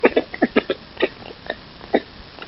Звуки лемура
А еще вот какой звук издает это животное